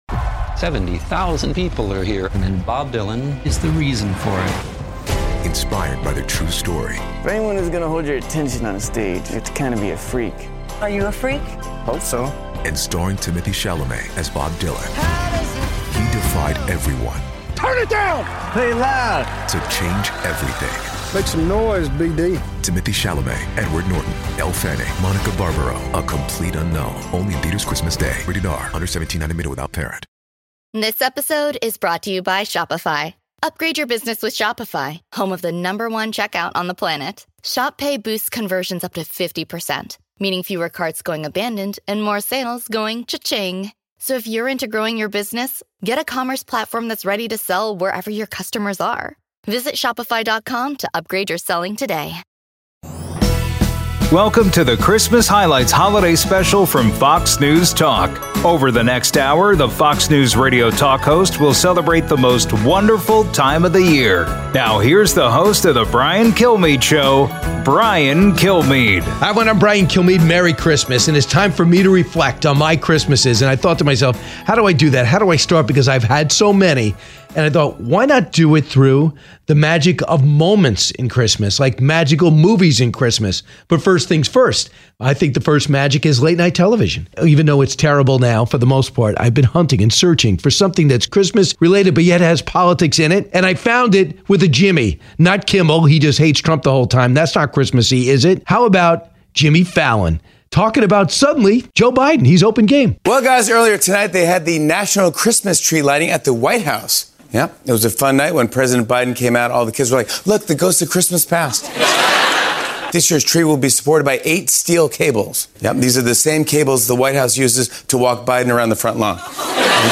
Hosted by Brian Kilmeade, Jimmy Failla, Will Cain and Guy Benson.